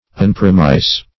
Search Result for " unpromise" : The Collaborative International Dictionary of English v.0.48: Unpromise \Un*prom"ise\, v. t. [1st pref. un- + promise.] To revoke or annul, as a promise.